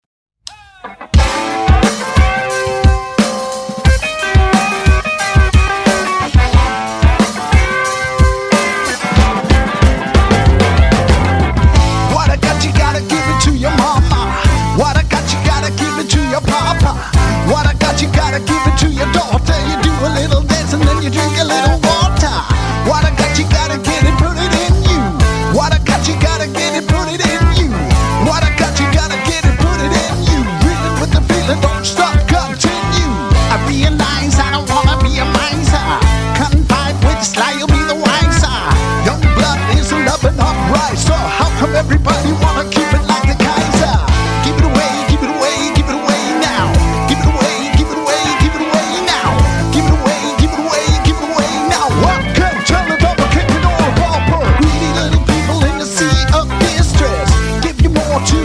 • Rock
Band
Intens Californisk Funk
Sveddryppende Californisk funk
Loud. Raw. Alive.